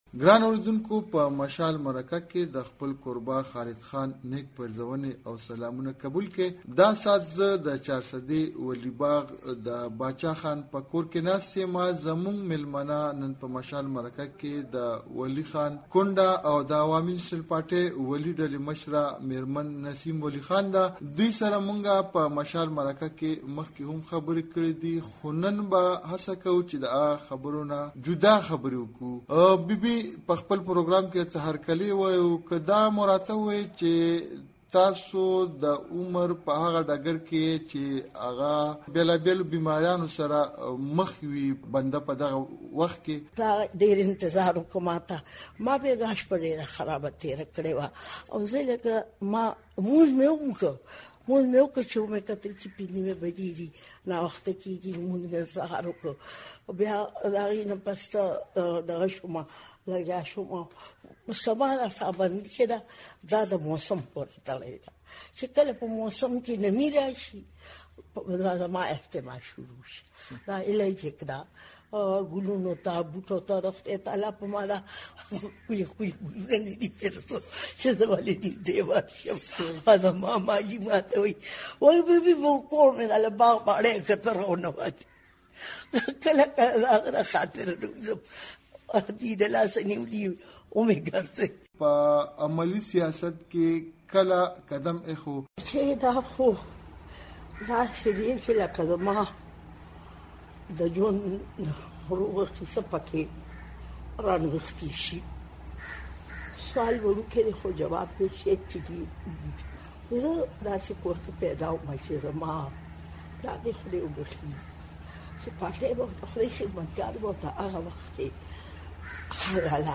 مشال مرکه / بیګم نسیم ولي خان